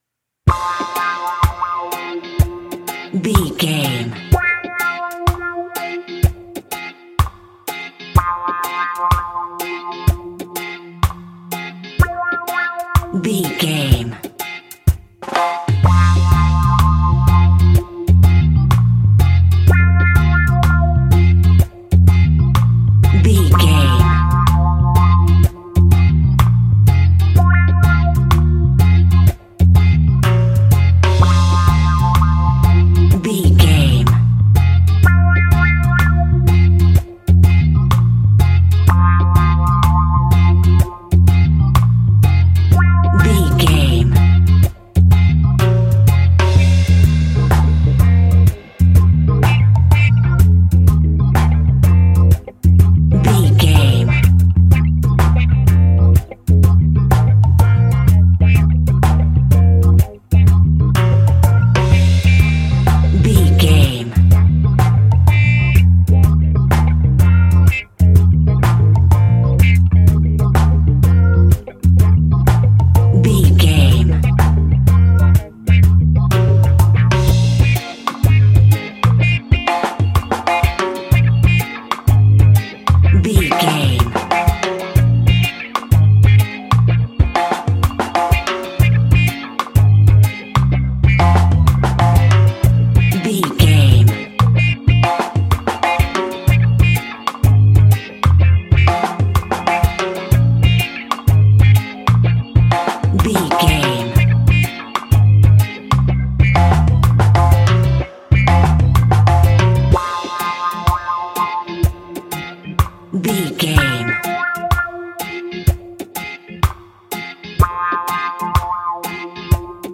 Classic reggae music with that skank bounce reggae feeling.
Aeolian/Minor
dub
laid back
chilled
off beat
skank guitar
hammond organ
percussion
horns